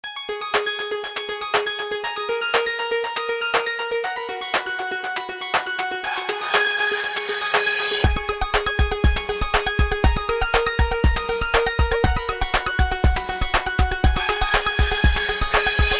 zuk-space-bird_24603.mp3